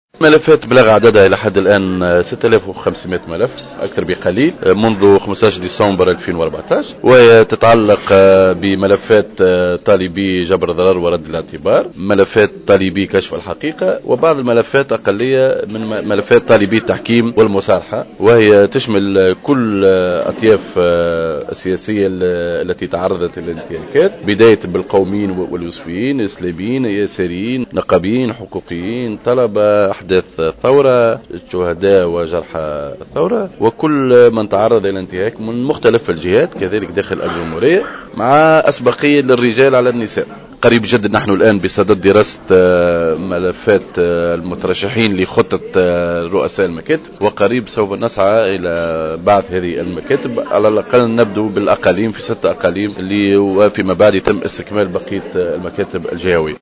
Le vice-président de l’instance vérité et dignité (IVD) Khaled Krichi, a indiqué ce jeudi 12 février 2015 dans une intervention sur les ondes de Jawhara FM, que le nombre des dossiers reçus jusqu’à ce jour a atteint les 6500.